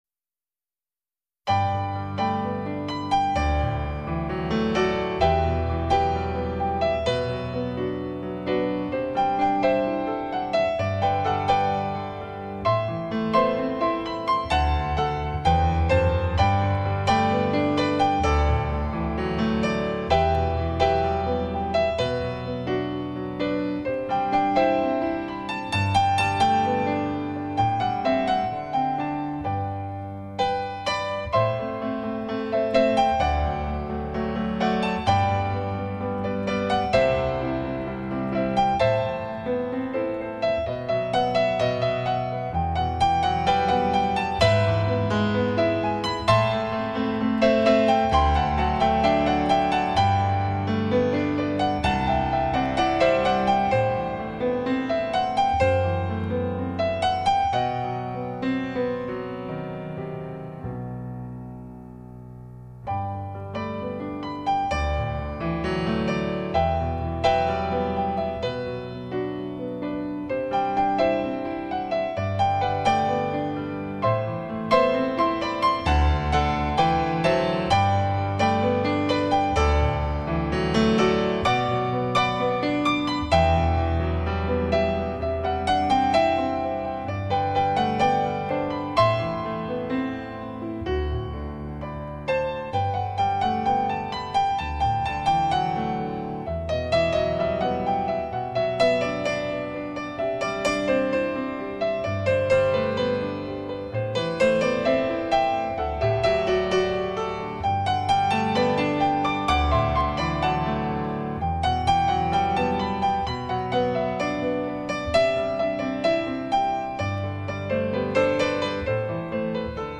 语言：纯音乐
类型：钢琴演奏
新世纪浪漫演奏曲风